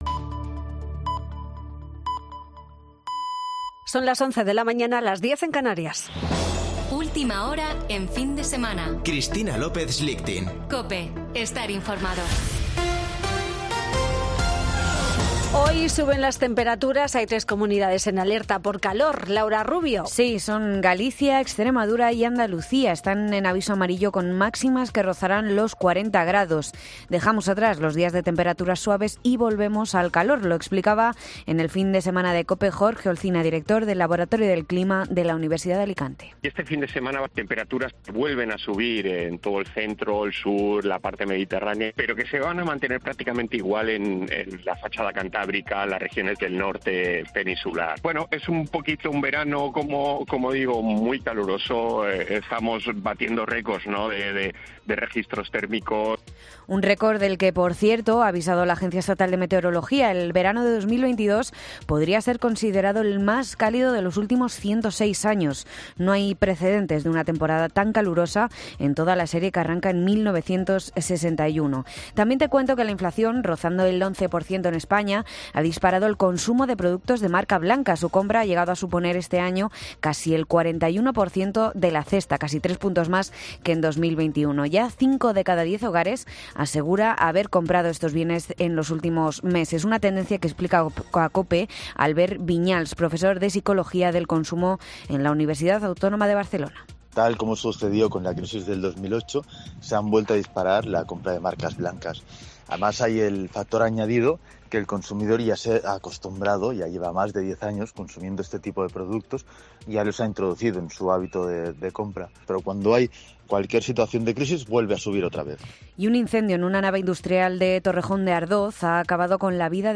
Boletín de noticias de COPE del 20 de agosto de 2022 a las 11.00 horas